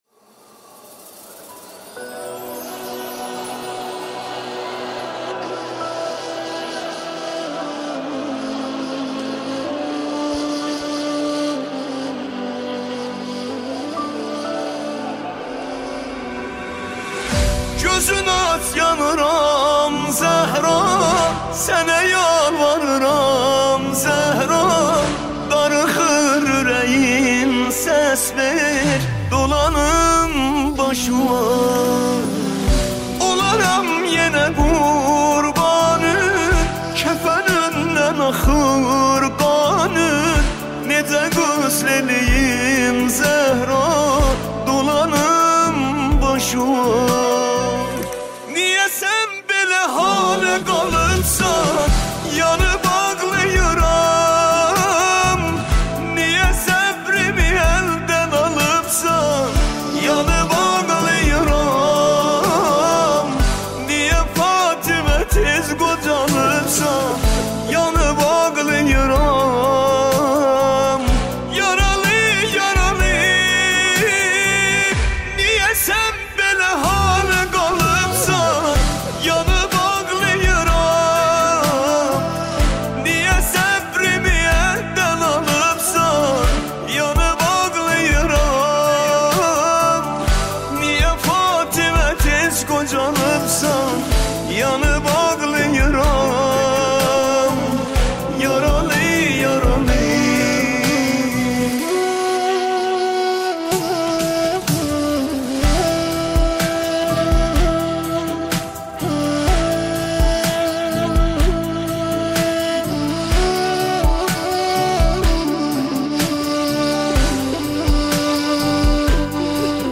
مداحی ترکی
نوحه فاطمیه